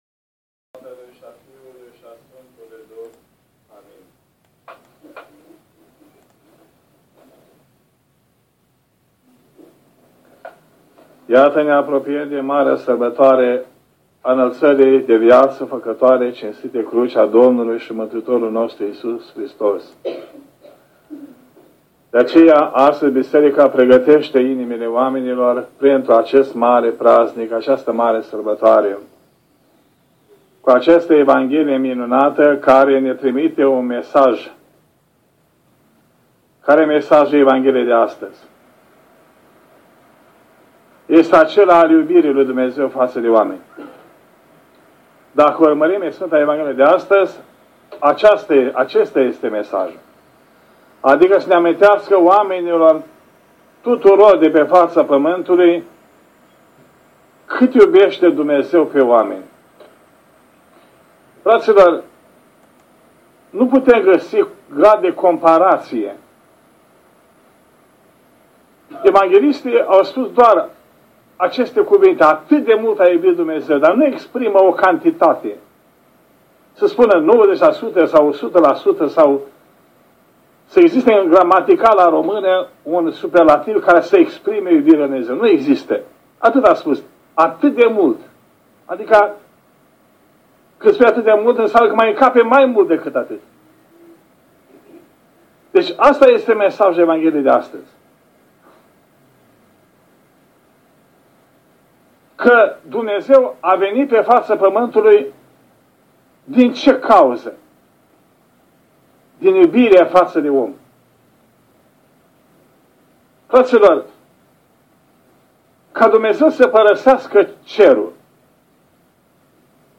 Predica
predică